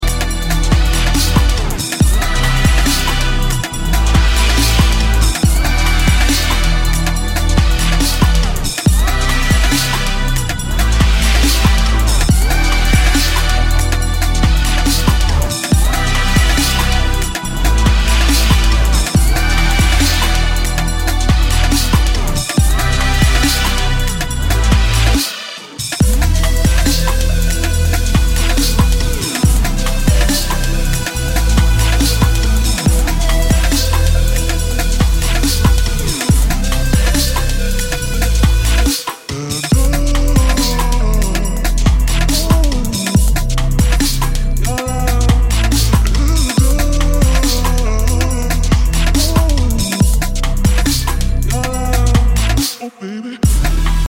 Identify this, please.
Kategorien: Elektronische